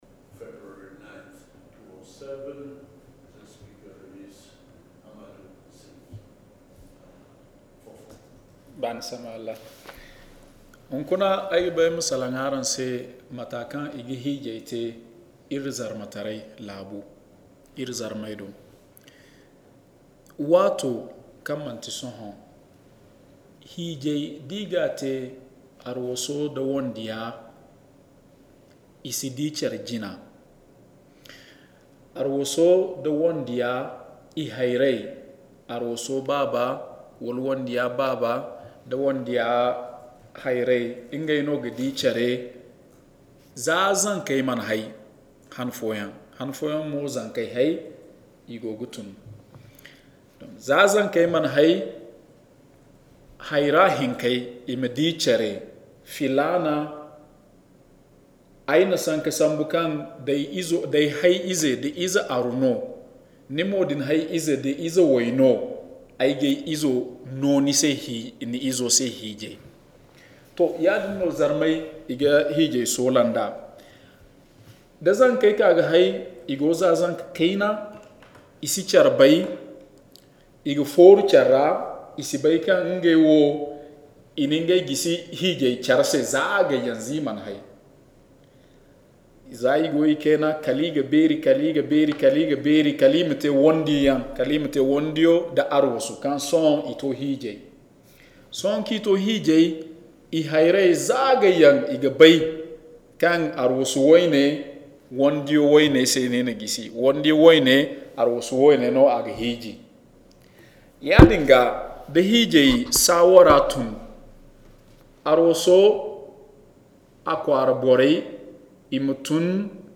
Zarma_Narrative.mp3